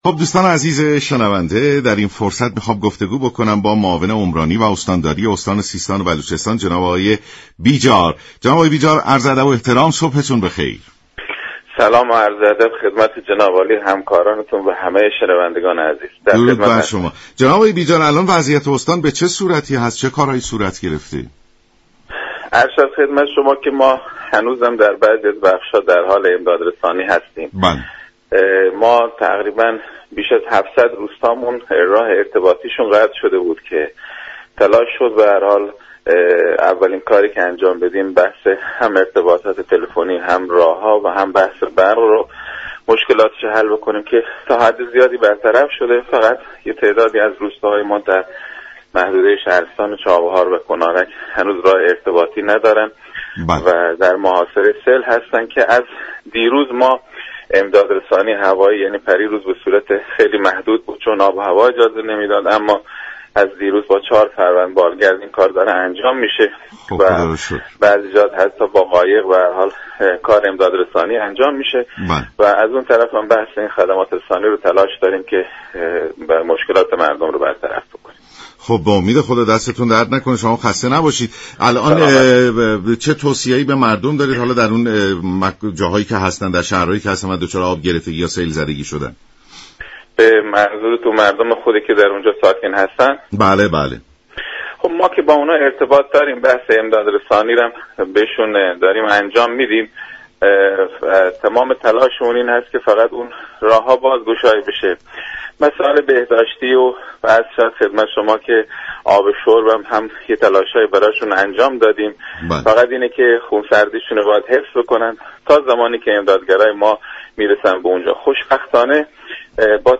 به گزارش شبكه رادیویی ایران، «منصور بیجار» معاون هماهنگی امور عمرانی استانداری سیستان و بلوچستان در برنامه «سلام صبح بخیر» به آخرین اقدامات صورت گرفته برای مهار سیل در استان اشاره كرد و با بیان اینكه امدادرسانی ها در برخی نقاط همچنان ادامه دارد، گفـت: سیل اخیر در استان سیستان و بلوچستان راه های ارتباطی بیش از 700 روستا را مسدود كرد.